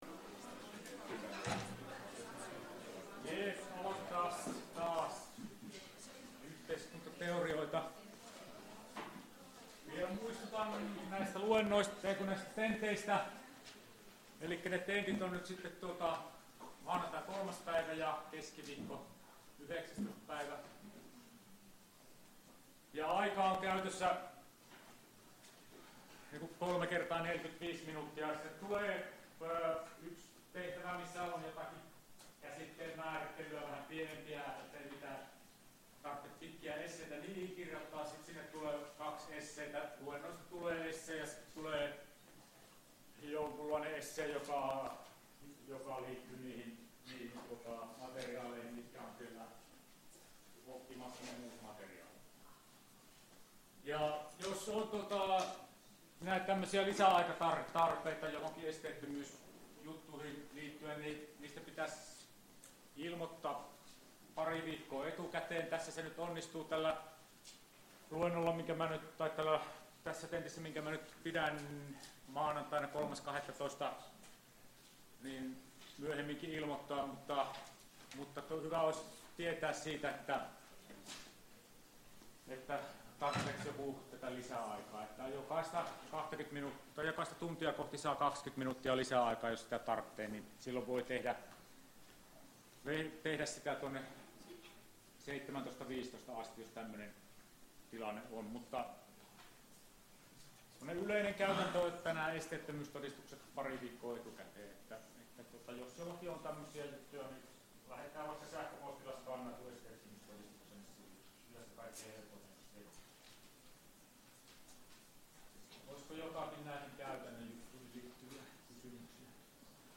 Luento 19.11.2018 — Moniviestin